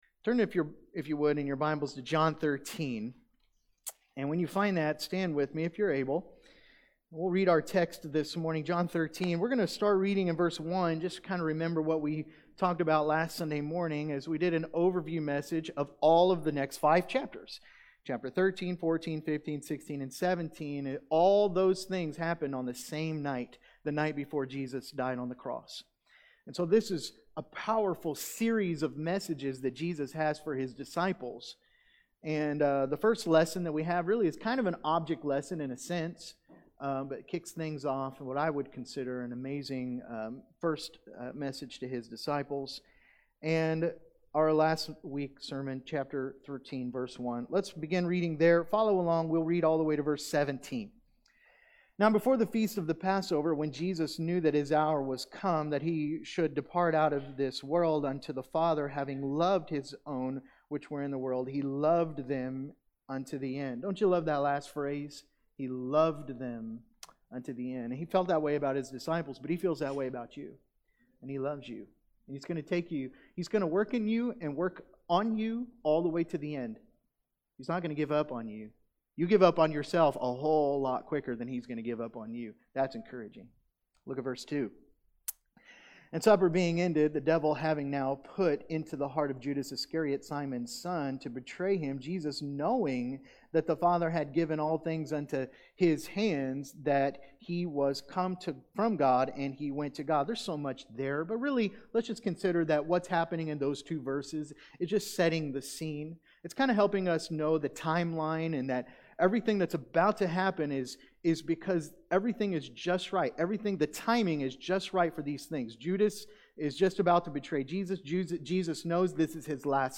Sunday AM